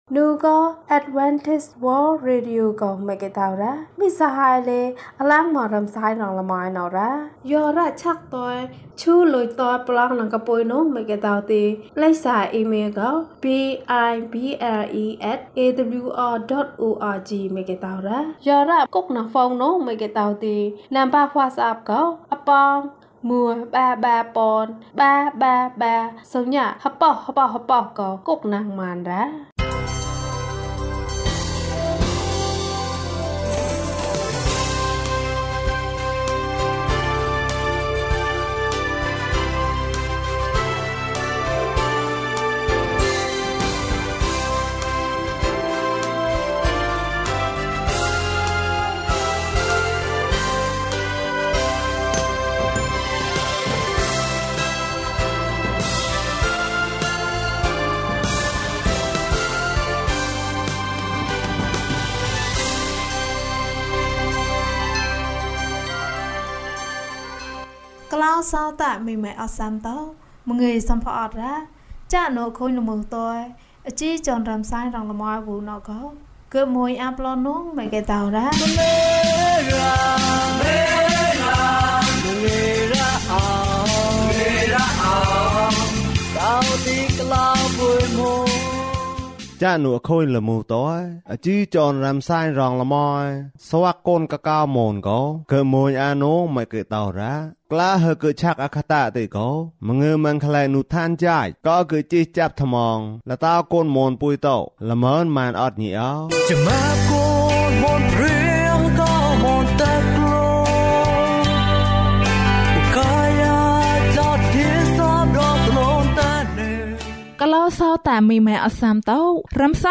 ခရစ်တော်ထံသို့ ခြေလှမ်း။၄၇ ကျန်းမာခြင်းအကြောင်းအရာ။ ဓမ္မသီချင်း။ တရားဒေသနာ။